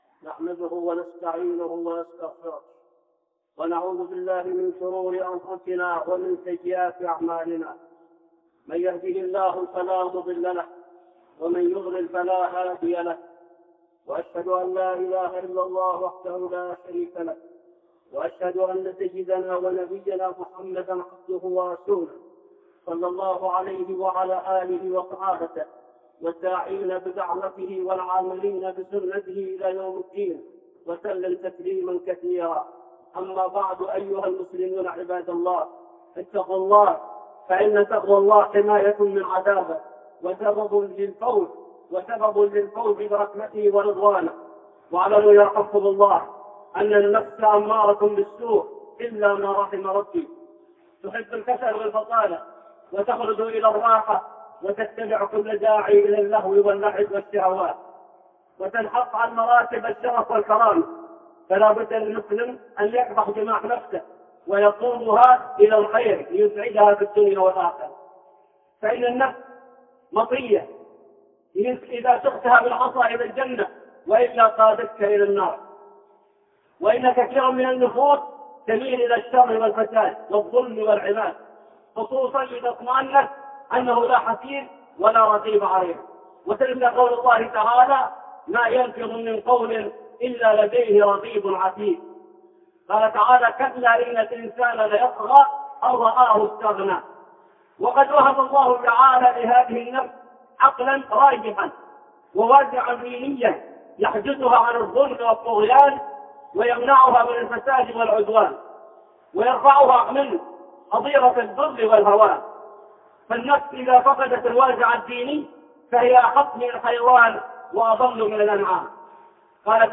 (خطبة جمعة) حامل المسك ونافخ الكير